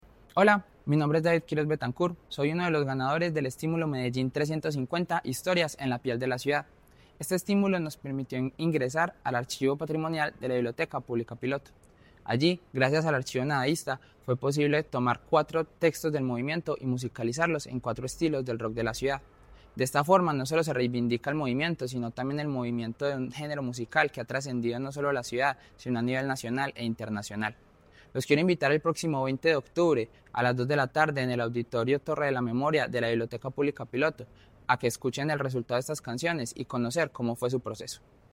Audio de Declaraciones